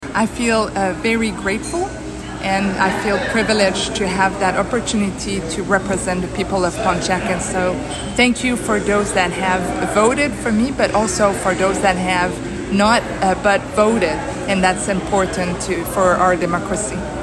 Following her commanding win, Chatel spoke with CHIP 101.9 about the campaign and her next steps as the region’s representative.